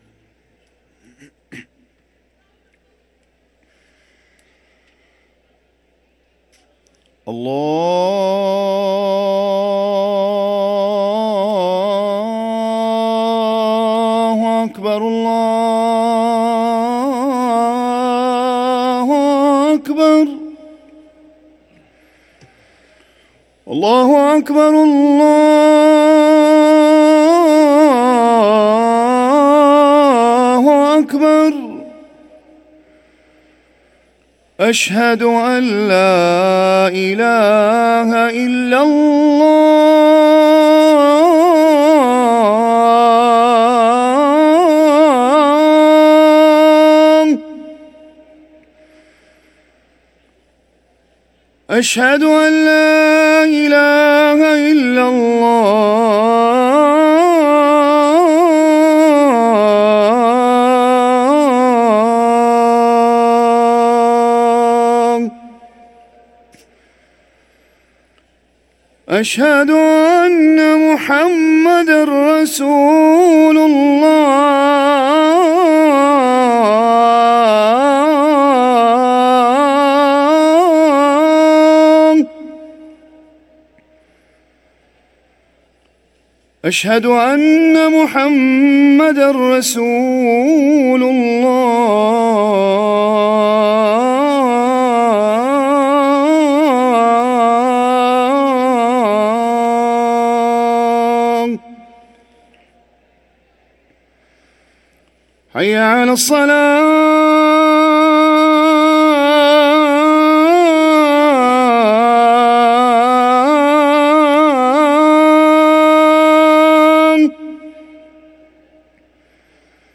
أذان العشاء